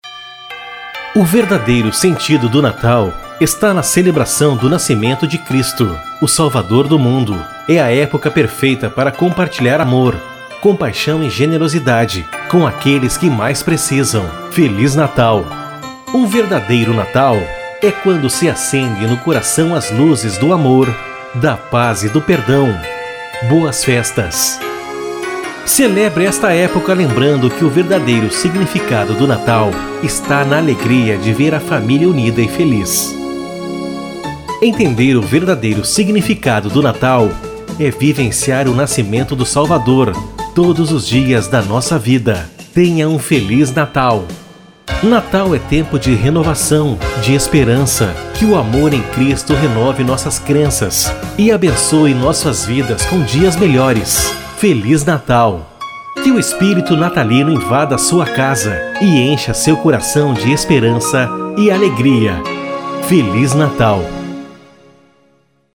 MENSAGENS NATALINAS: